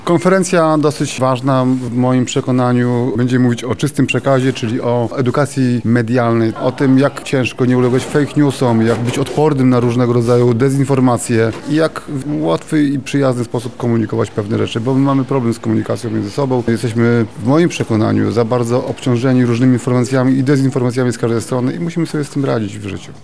Więcej o inicjatywie mówi Tomasz Szabłowski, Lubelski Kurator Oświaty: